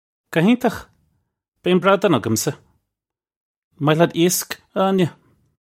Pronunciation for how to say
Guh hayn-tokh! Bey un brad-an uggum-sah! Un moy lyat eesk, ah Ah-nyeh? (U)